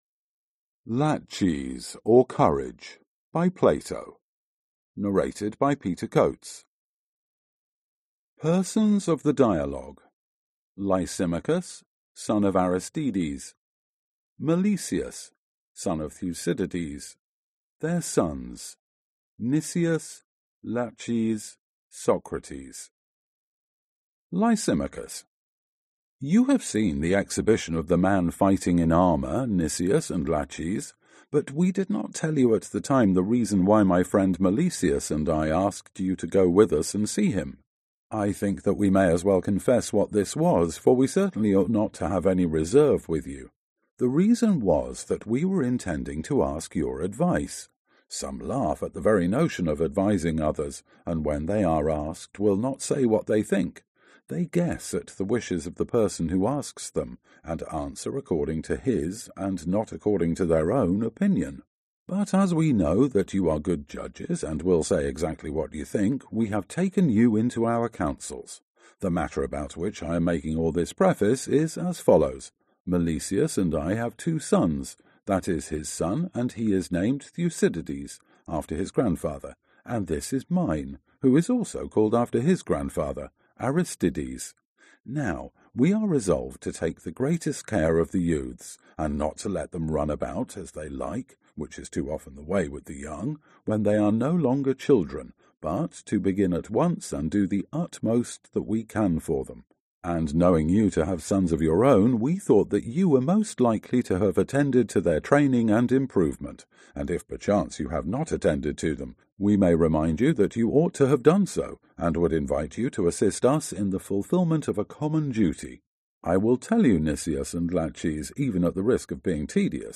Аудиокнига Laches | Библиотека аудиокниг